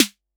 Tr8 Snare 03.wav